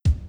上記動画の1:08頃から始まるバスドラムの音を考えてみよう．
drum3.wav